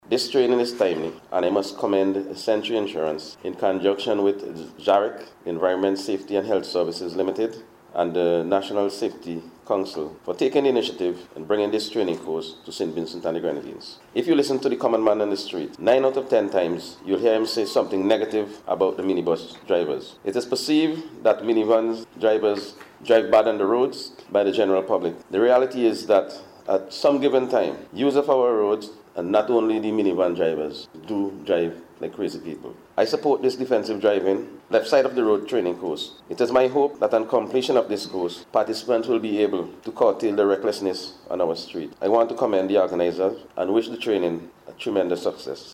And Police Commissioner Michael Charles commended the organizers for introducing the program, which he says will aid in curtailing the recklessness on the streets.